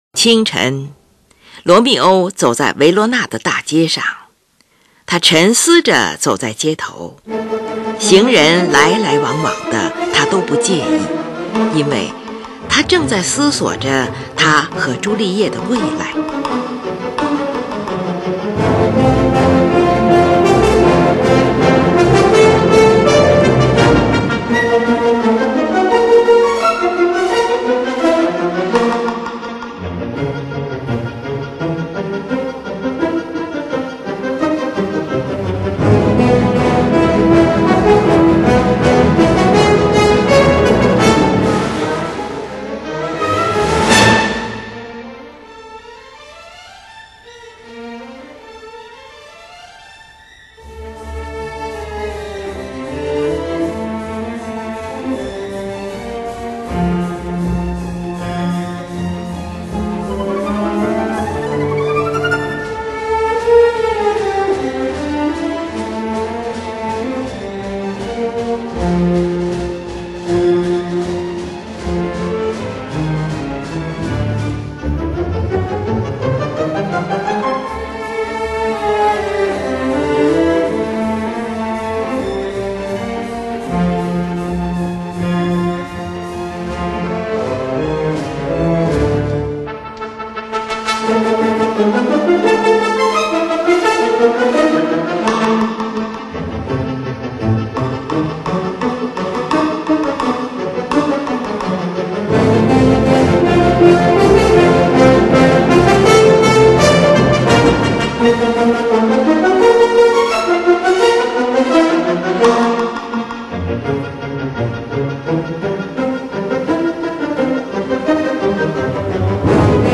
三幕芭蕾舞剧